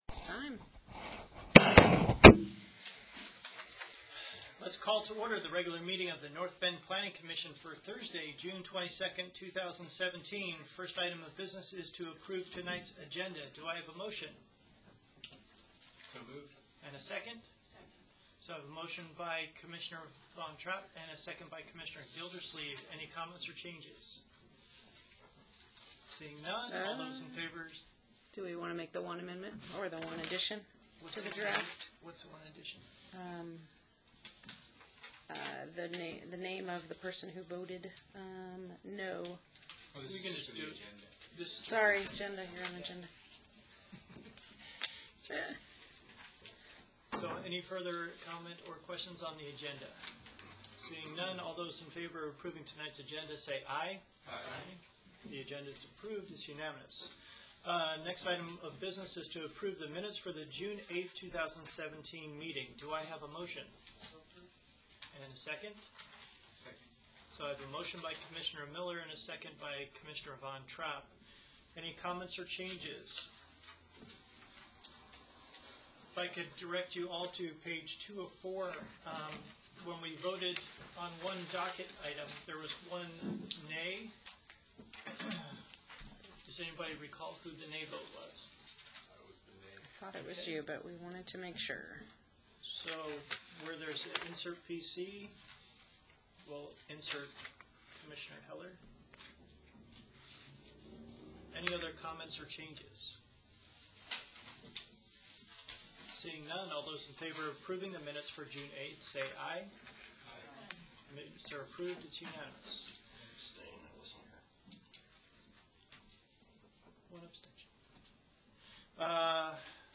Planning Commission Audio - June 22, 2017